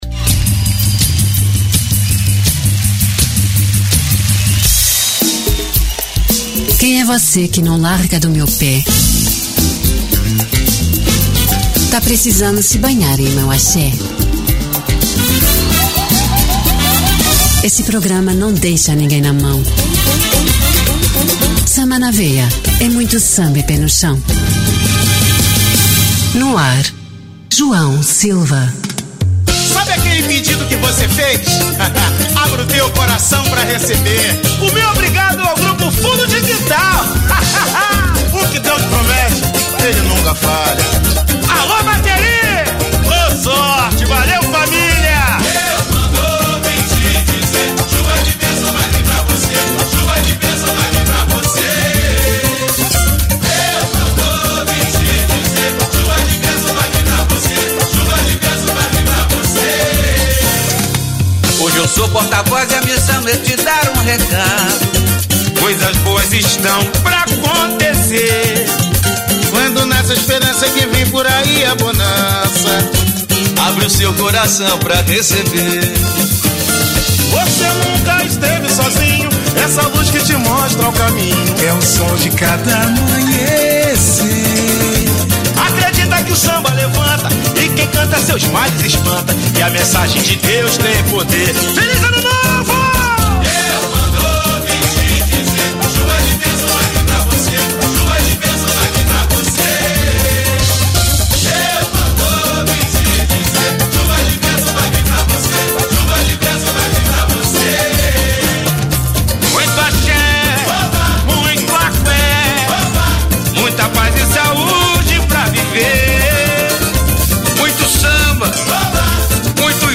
Samba de Raíz